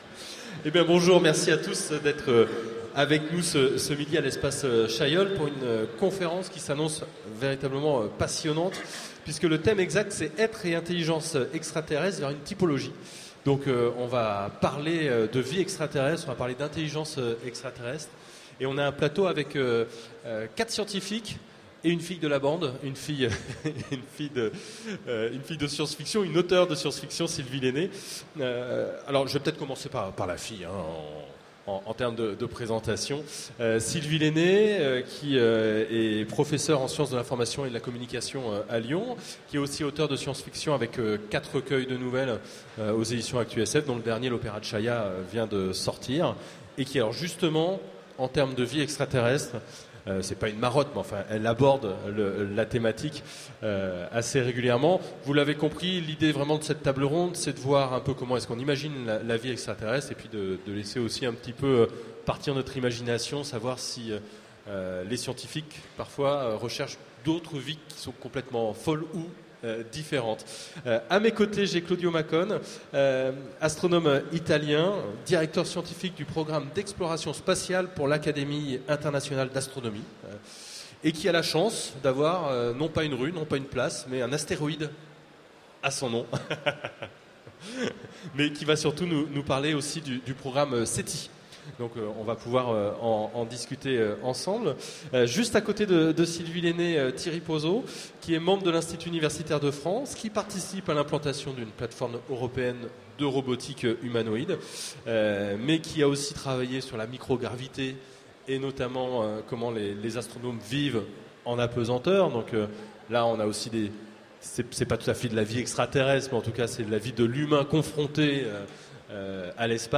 Mots-clés Extraterrestre Conférence Partager cet article